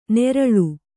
♪ neraḷu